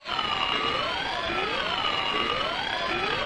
Sci-Fi Ambiences
AFX_BATTLESTATIONS_3_DFMG.WAV